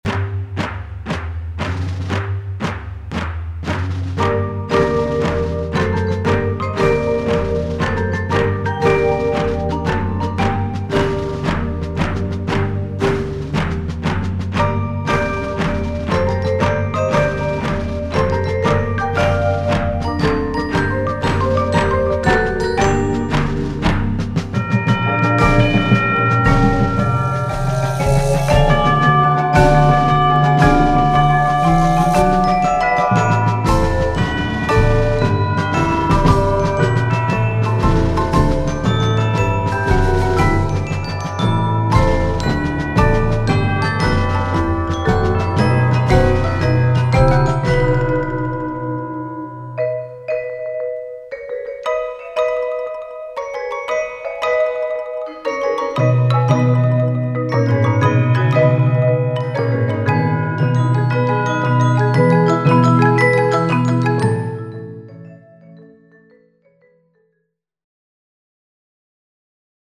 Easy-Medium Concert Ensemble